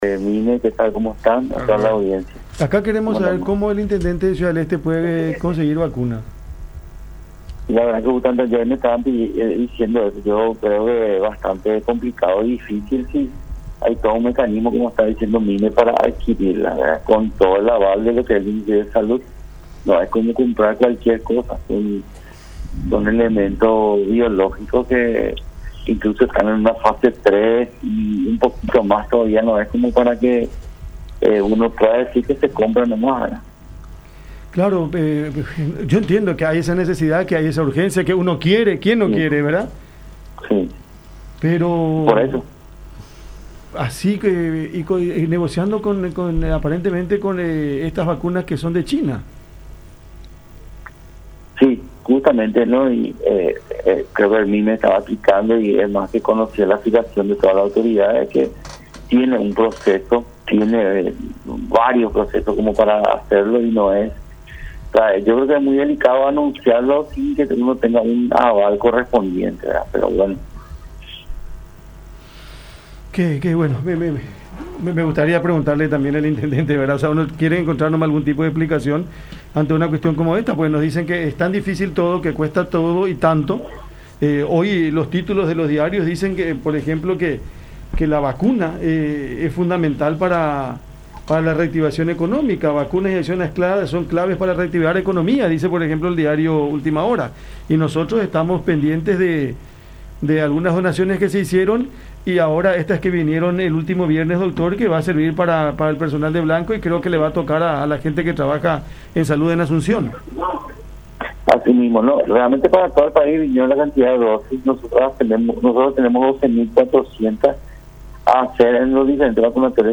“Nosotros ahora tenemos 12.400 dosis que comenzarán a ser aplicadas en todos los vacunatorios disponibles. Creemos que para el fin de semana completaremos la aplicación de vacunas de todos los trabajadores de la salud aquí de Asunción”, dijo Núñez en conversación con La Unión, trabajo que se hará con las dosis Covishield del laboratorio AstraZeneca, llegadas con el Mecanismo COVAX.